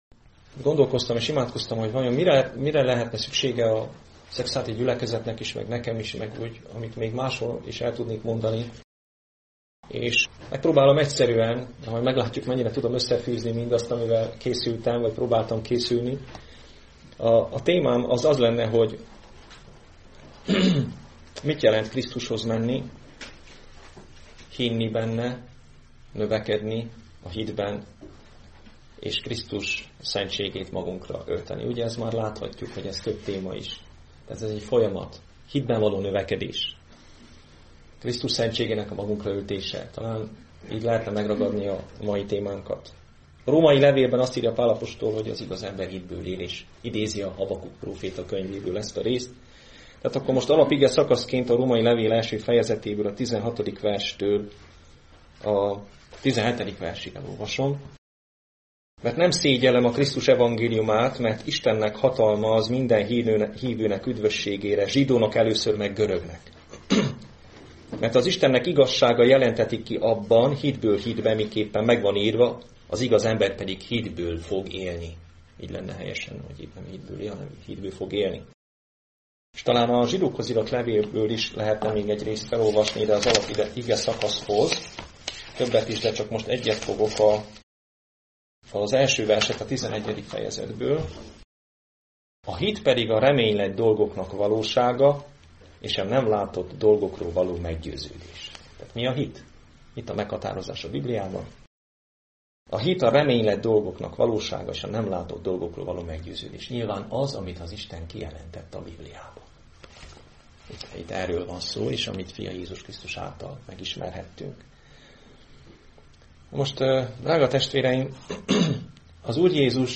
A hitben való növekedés Igehirdetések mp3 Link az igehirdetéshez Hasonló bejegyzések Igehirdetések mp3 Ébredj fel a lelki halálból és felragyog neked...